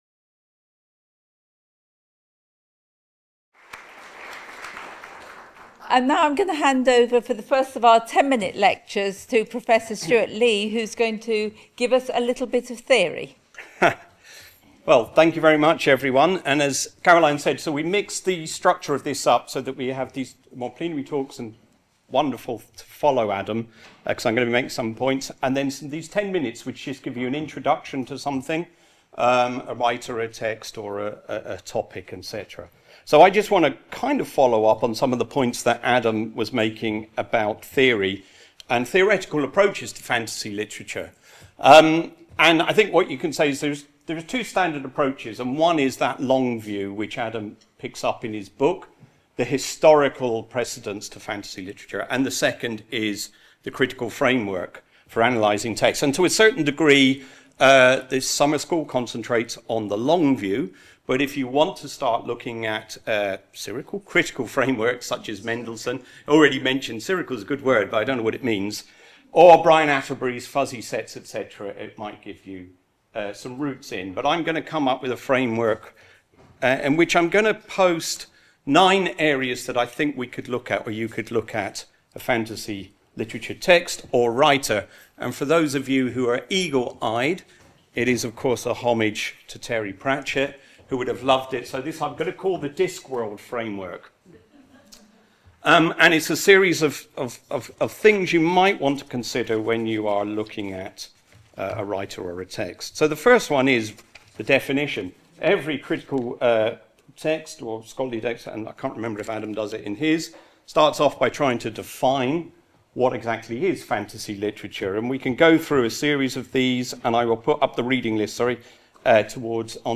Short talk (10 minutes) - Part of the Bloomsbury-Oxford Summer School (23rd-25th September 2025) held at Exeter College.